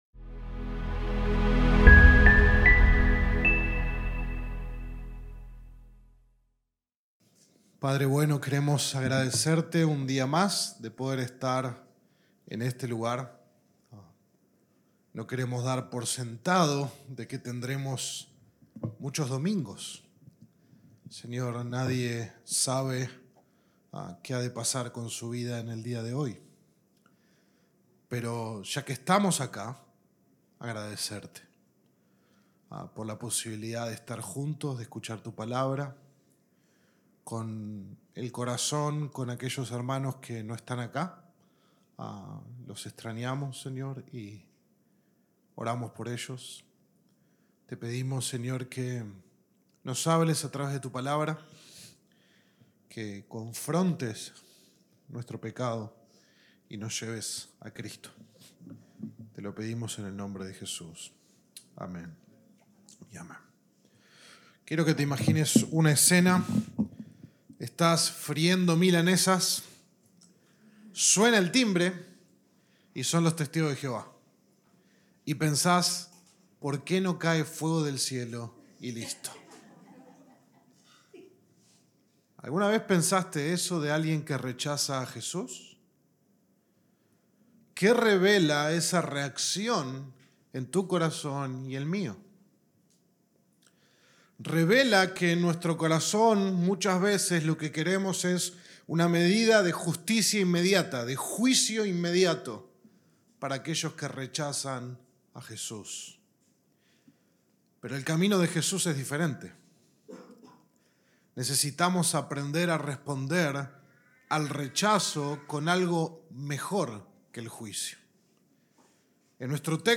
Sermón